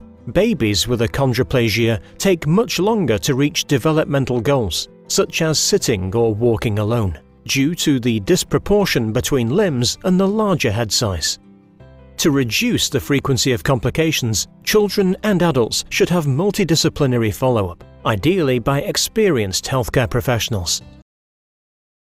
Medical narration is a skill that calls for professionalism and clarity.